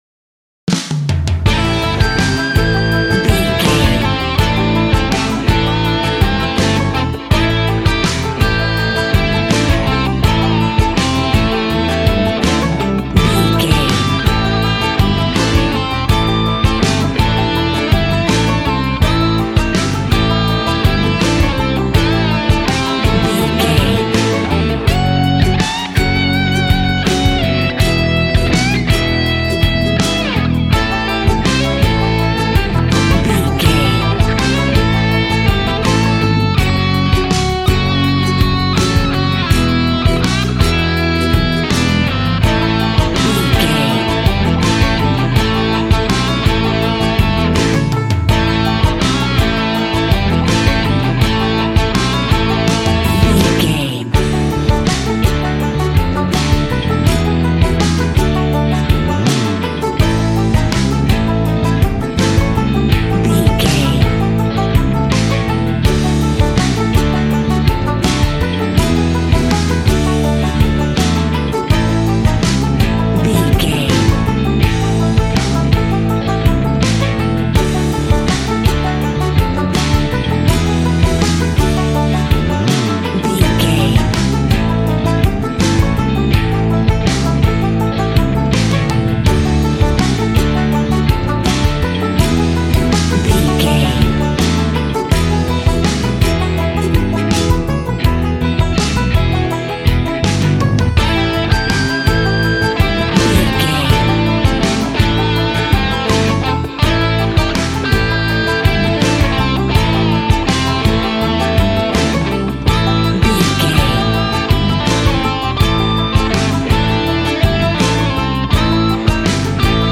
Ionian/Major
hard
groovy
powerful
electric guitar
bass guitar
drums
organ